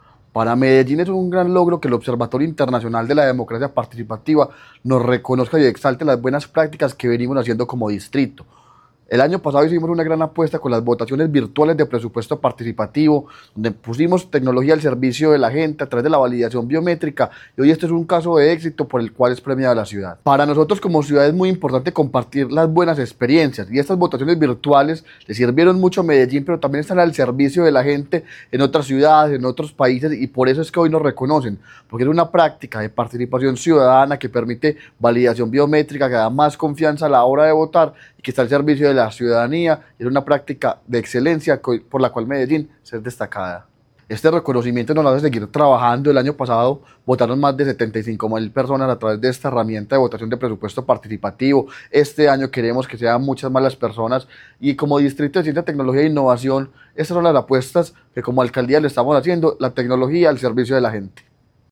Audio-Declaraciones-del-secretario-de-Participacion-Ciudadana-Camilo-Cano-Montoya-3.mp3